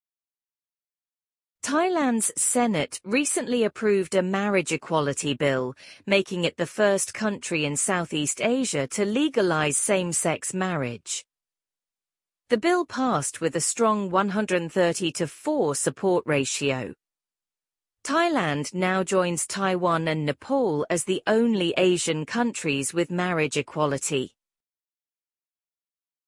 [CNN News Summary]
Try other AI voices